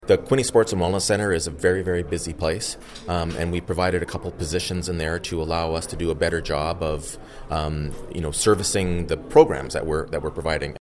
Following the one day session, Panciuk told a media scrum he wants to see the procedure for setting a budget changed next year, involving a committee process.